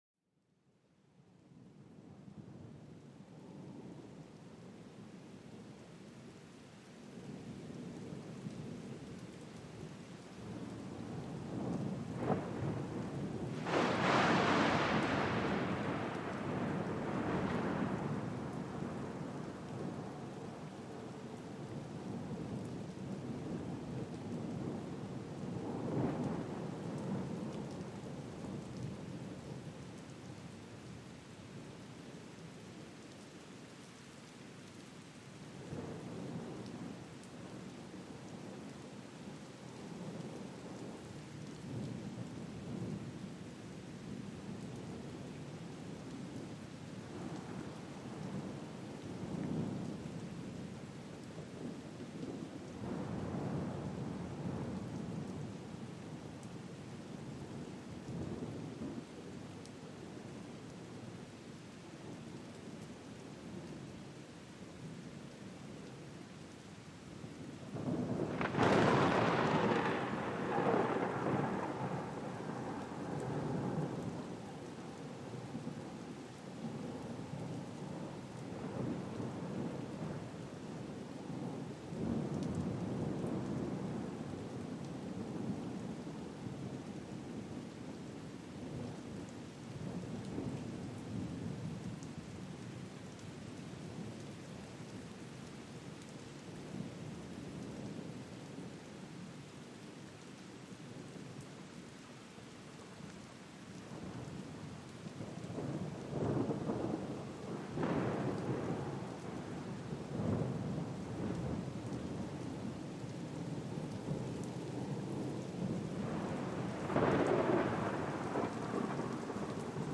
Sumérgete en los sonidos de una tormenta lejana, con truenos retumbantes y el suave golpeteo de la lluvia. Cada trueno resuena suavemente, mientras las gotas de lluvia crean una atmósfera serena.